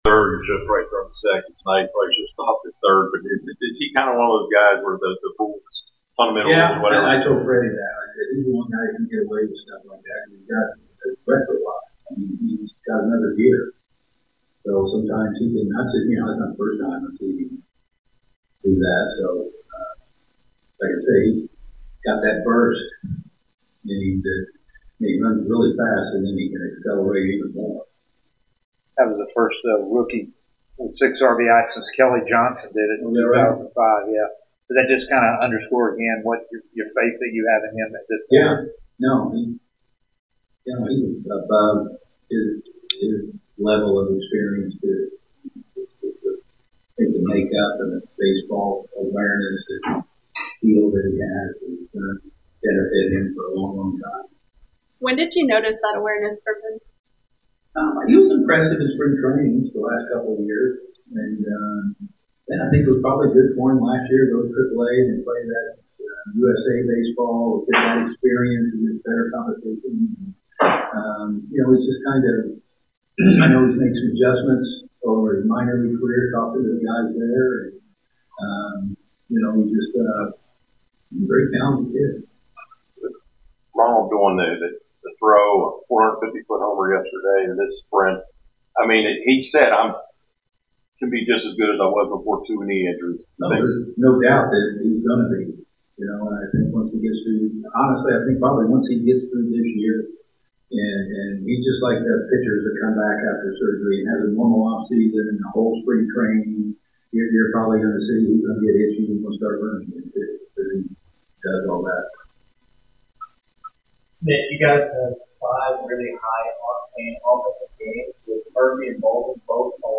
Atlanta Braves Manager Brian Snitker Postgame Interview after defeating the San Francisco Giants at Truist Park.